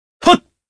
Kain-Vox_Jump_jp.wav